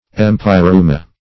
Search Result for " empyreuma" : The Collaborative International Dictionary of English v.0.48: Empyreuma \Em`py*reu"ma\, n. [NL., from Gr.